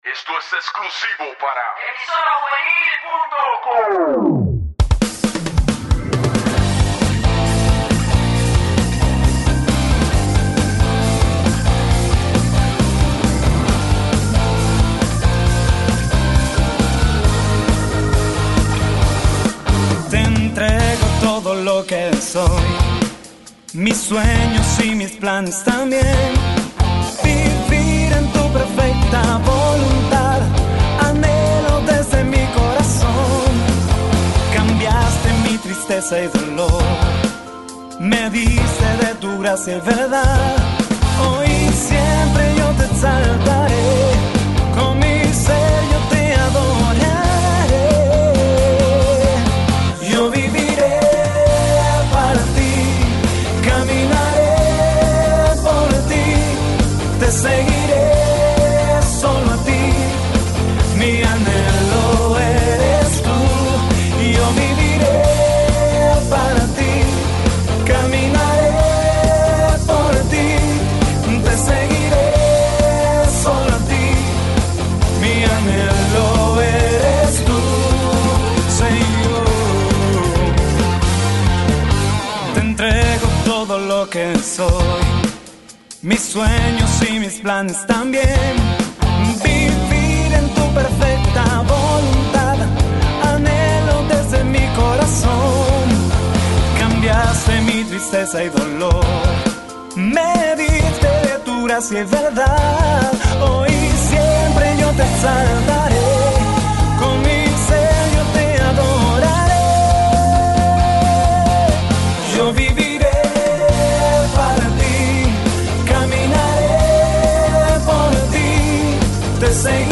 Cantante, Compositor y Músico Cristiano
Góspel Rock